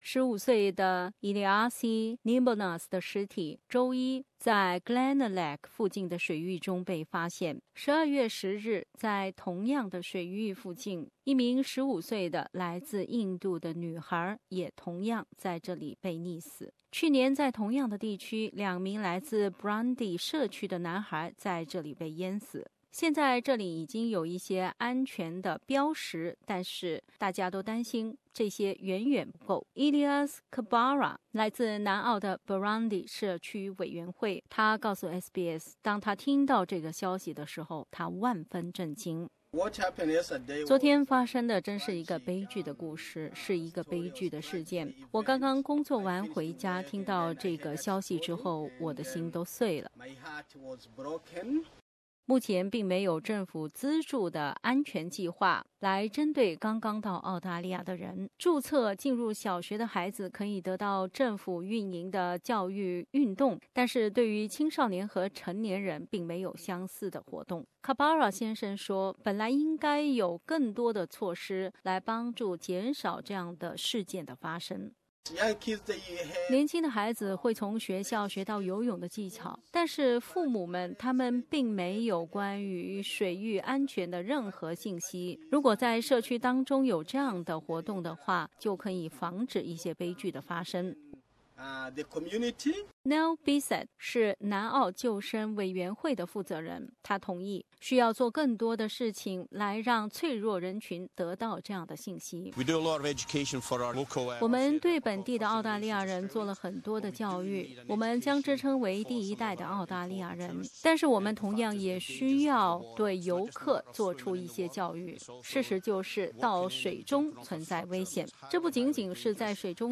03:24 Source: AAP SBS 普通话电台 View Podcast Series Follow and Subscribe Apple Podcasts YouTube Spotify Download (1.56MB) Download the SBS Audio app Available on iOS and Android 在南澳一个最知名的海滩，八天内出现了两起溺亡事故，引起社区广泛关注。